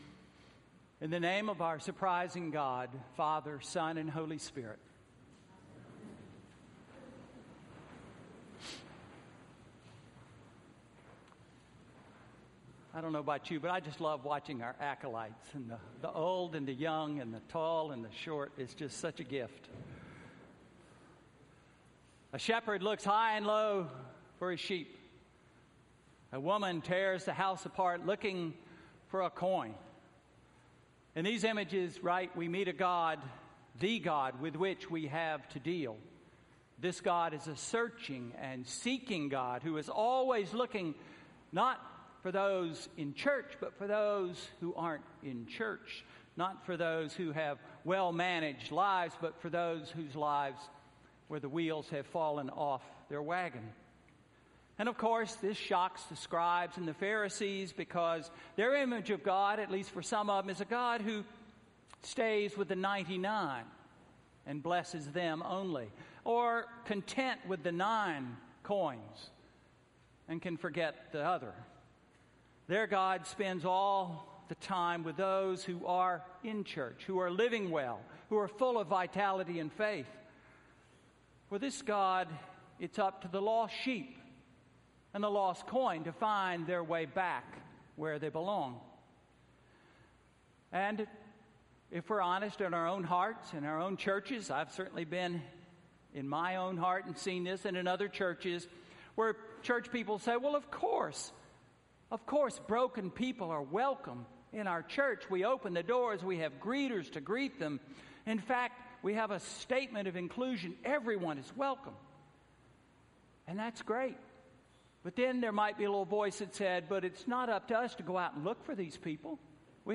Sermon–September 11, 2016